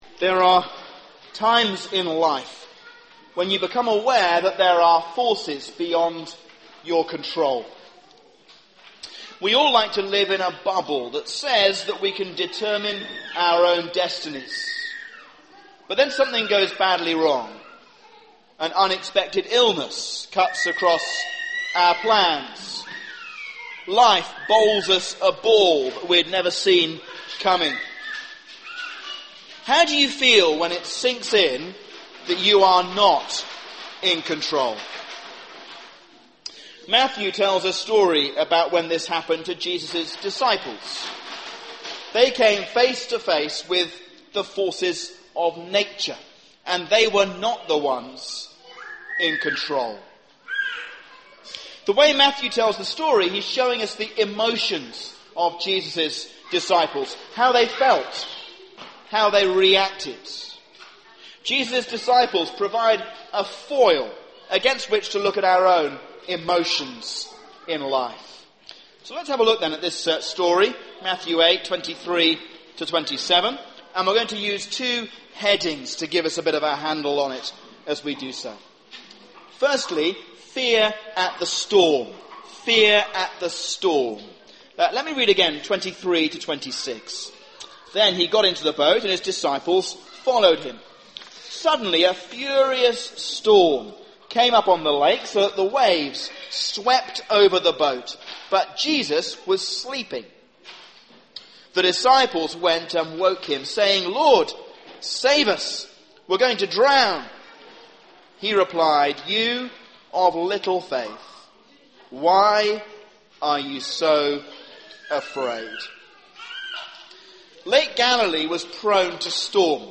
A sermon on Matthew 8:23-27